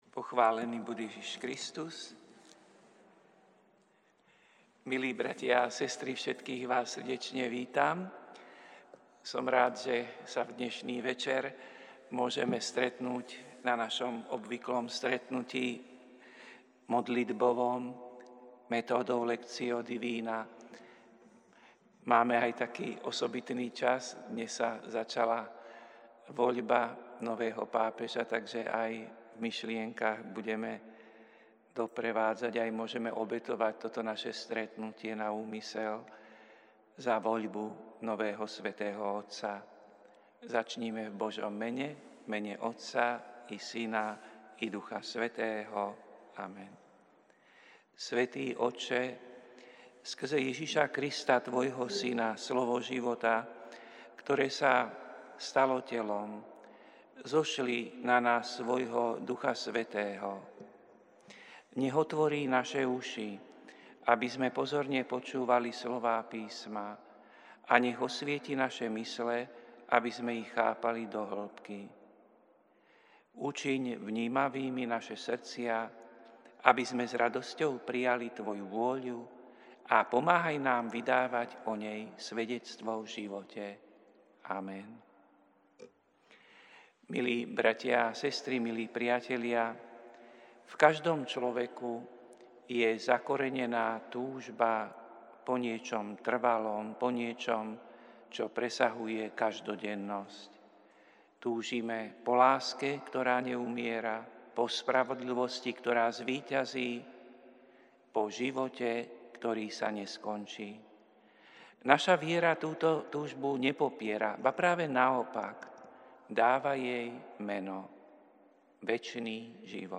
Prinášame plný text a audio záznam z Lectio divina, ktoré odznelo v Katedrále sv. Martina 7. mája 2025.